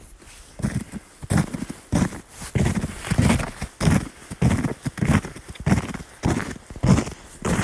Stampfenden Schrittes geht es zurück in Richtung Blockhaus.
So hört sich der Marsch über den zugefrorenen See an:
Die stapfenden Schritte über den See waren deutlich zu vernehmen , und der Angstschweiß wehte bis hierher !